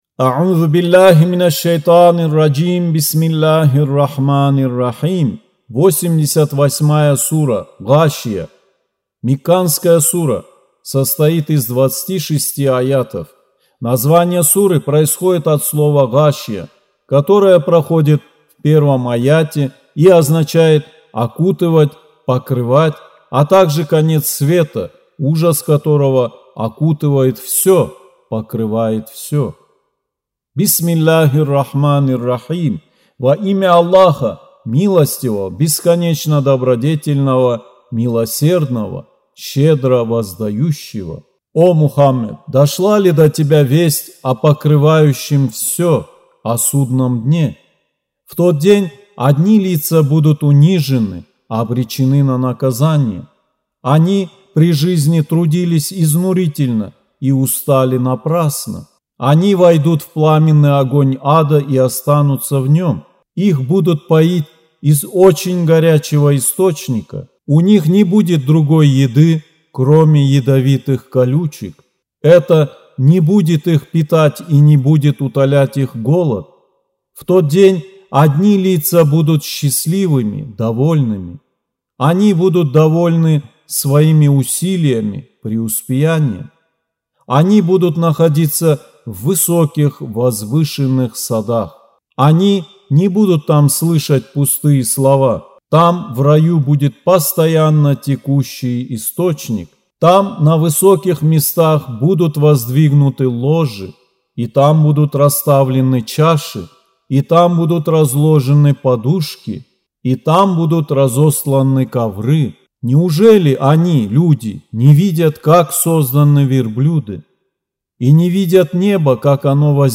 Аудио Коран 88.